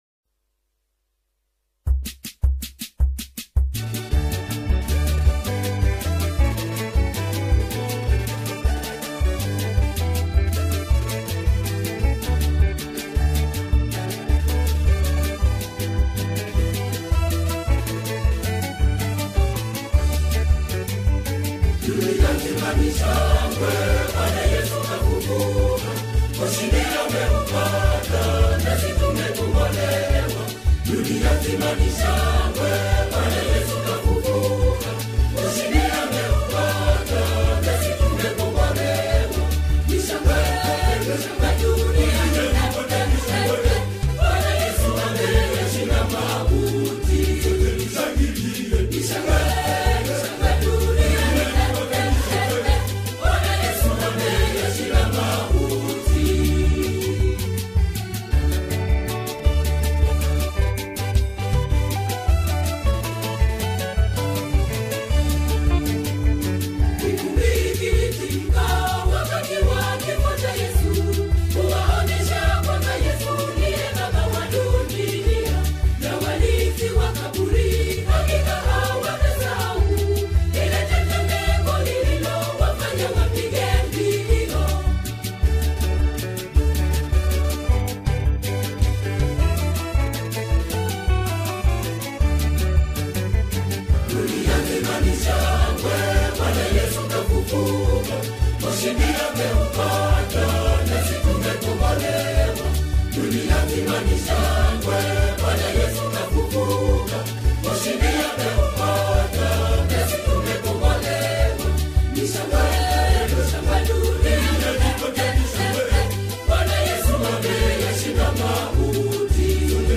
Nyimbo za Dini music
Gospel music